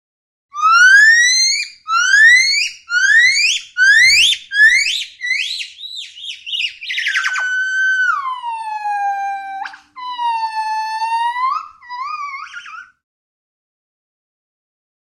Здесь вы найдете разнообразные крики, песни и коммуникационные сигналы этих обезьян, записанные в естественной среде обитания.
Громкий вопль гиббона